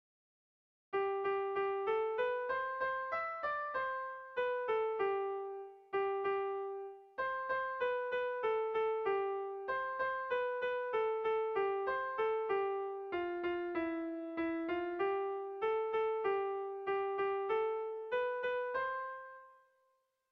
Dantzakoa
Zortziko txikia (hg) / Lau puntuko txikia (ip)
A1A2BA2